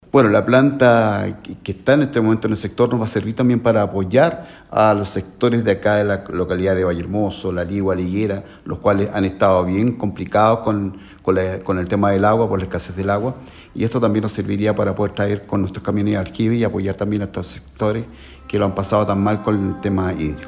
Así lo señaló Patricio Pallares, alcalde de La Ligua: